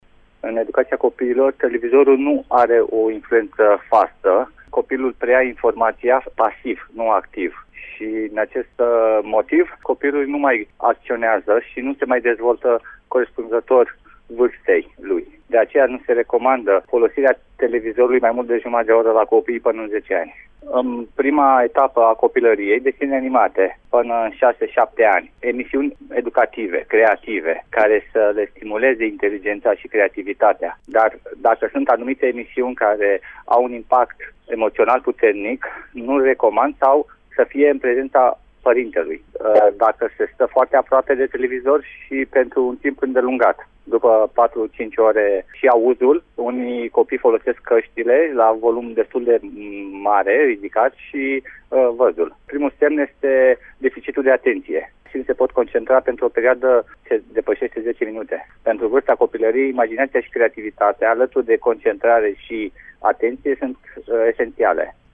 Părinții sunt de acord cu faptul că televizorul nu are un rol educativ.